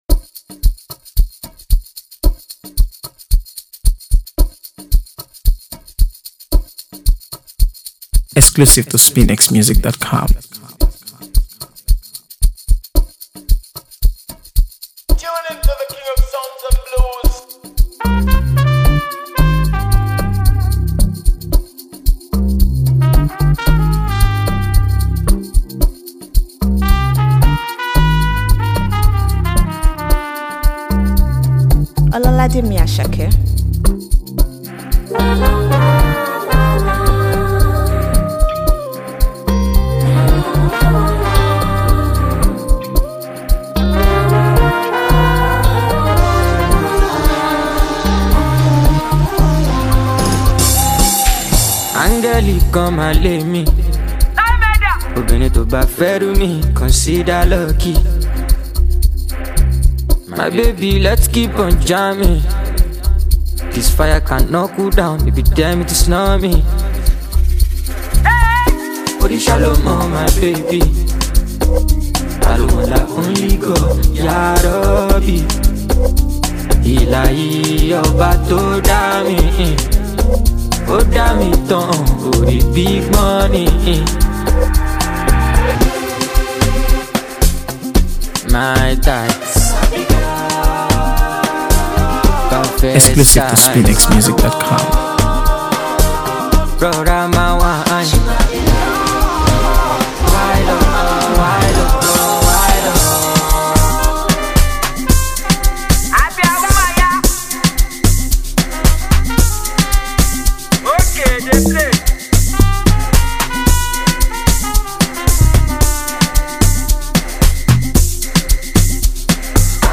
AfroBeats | AfroBeats songs
With its infectious melody and heartfelt lyrics